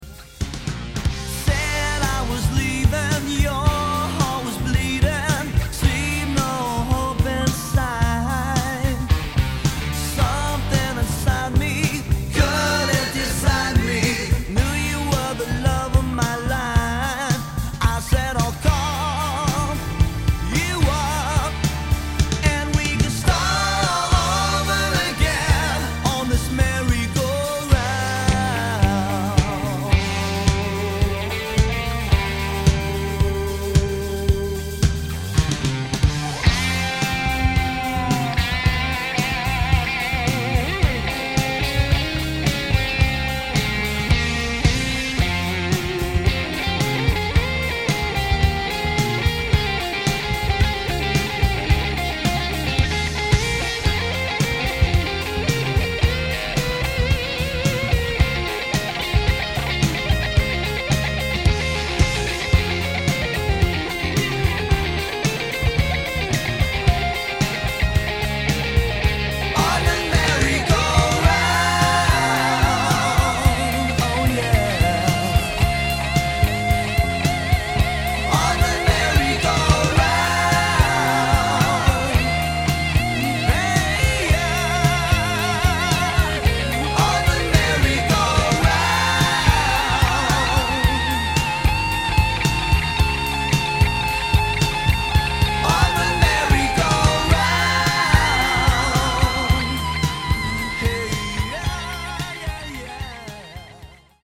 70s Pop/Rock